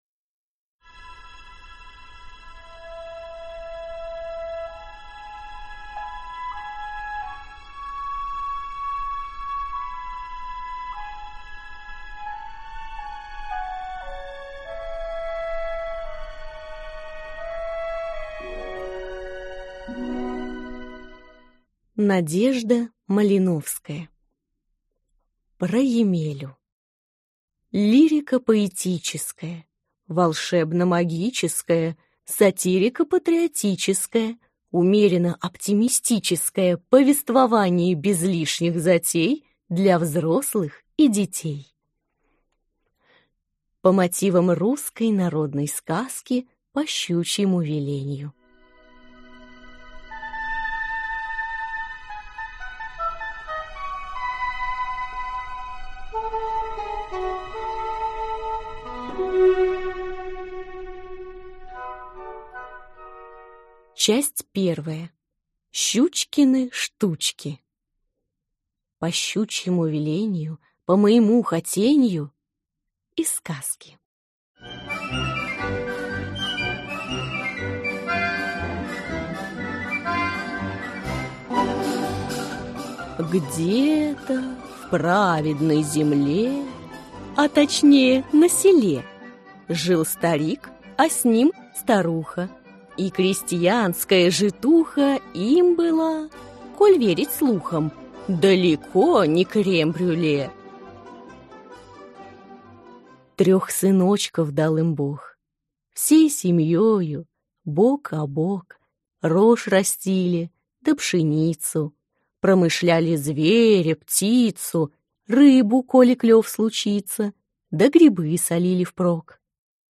Aудиокнига Про Емелю